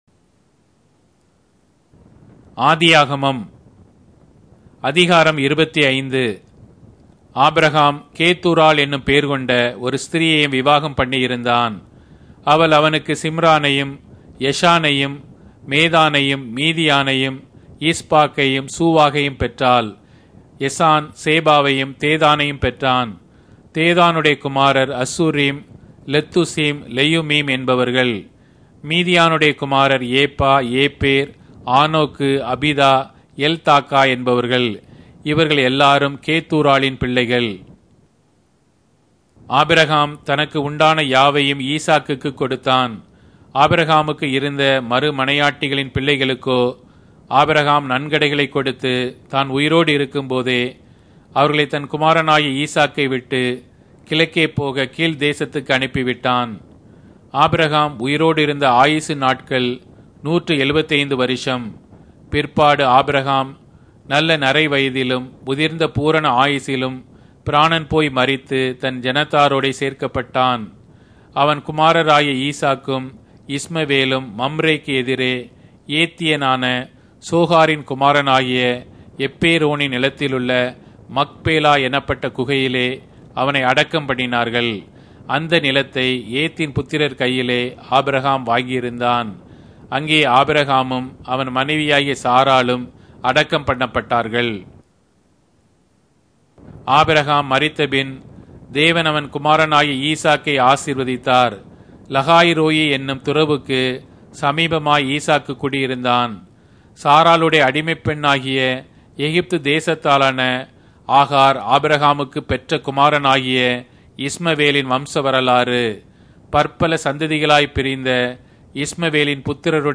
Tamil Audio Bible - Genesis 1 in Gnterp bible version